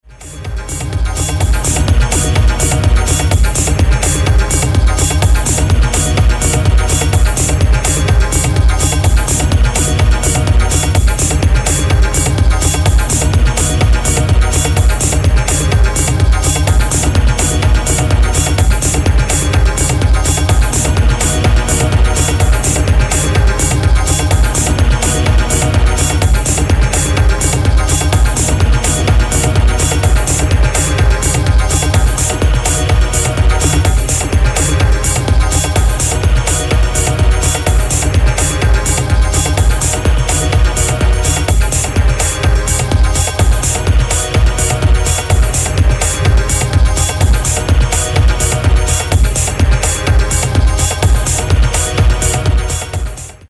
Again he shows his passion for Detroit techno.